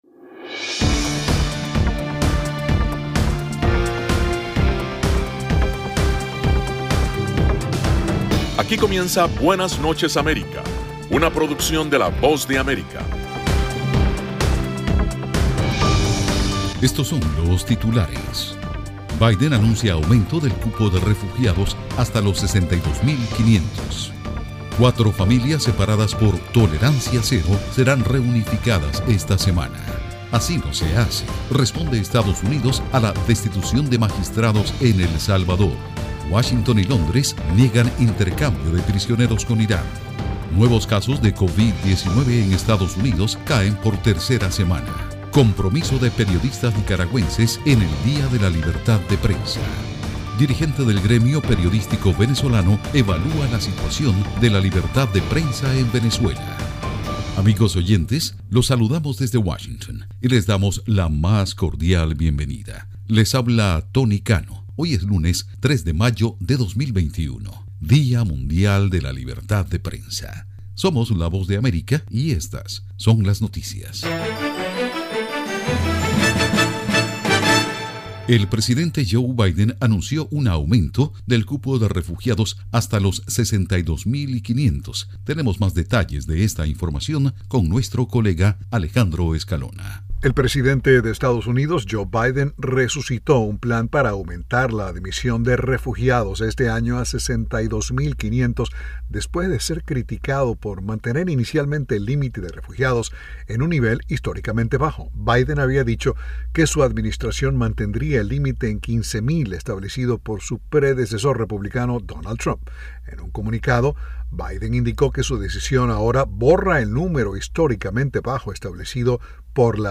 PROGRAMA INFORMATIVO DE LA VOZ DE AMERICA, BUENAS NOCHES AMERICA.